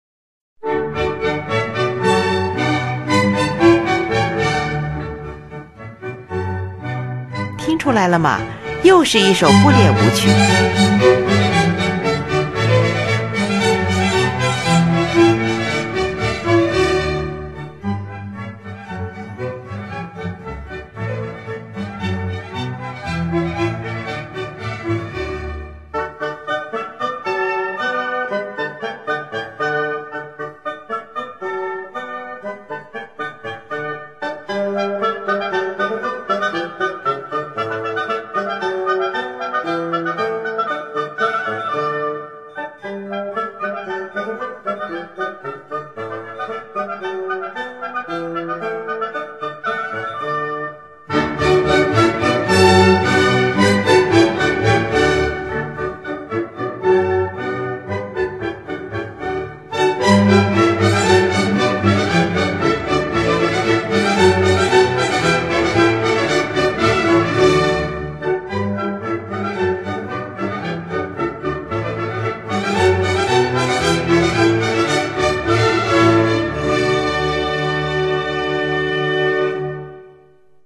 是一部管弦乐组曲
乐器使用了小提琴、低音提琴、日耳曼横笛、法兰西横笛、双簧管、圆号、小号等